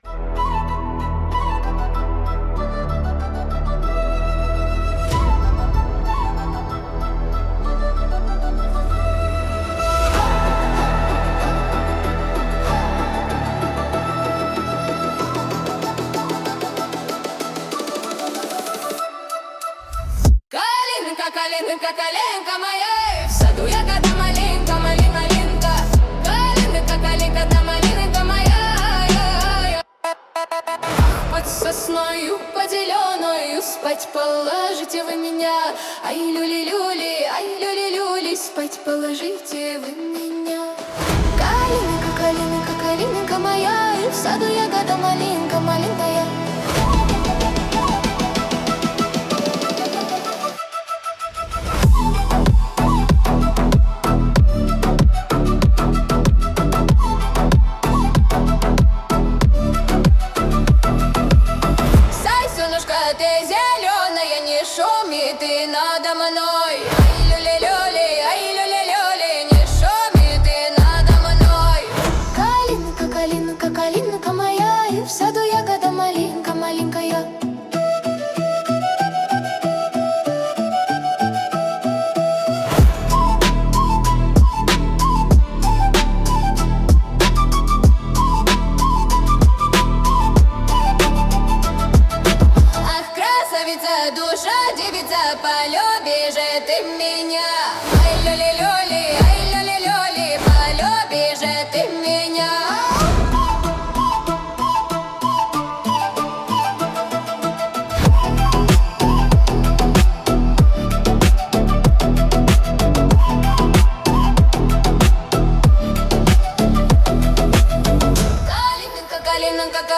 традиционная народная песня - Slavic Folk Remix